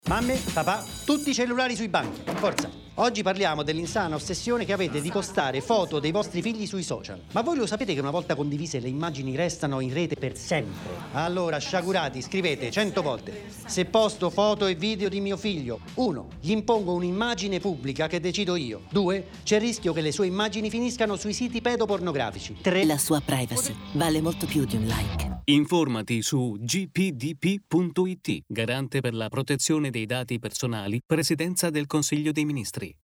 Lo spot radio
sharenting_radio.mp3